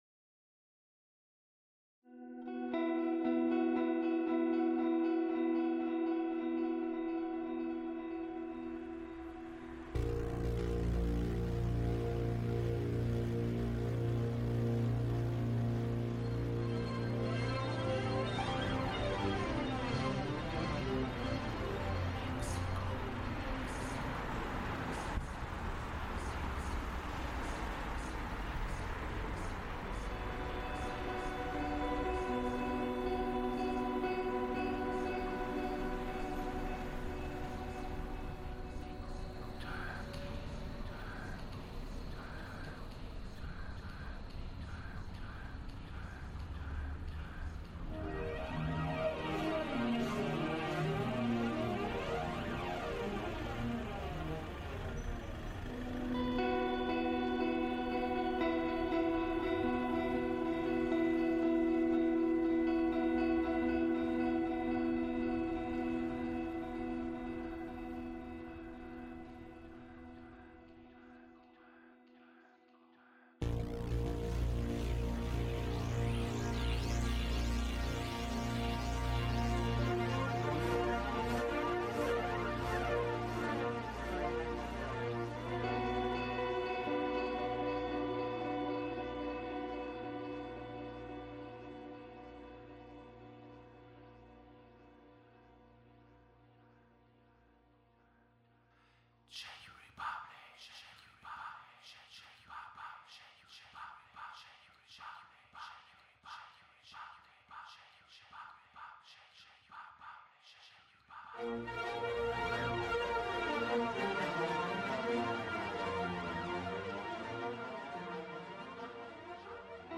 Fresh-Legs_Soundscape-2.mp3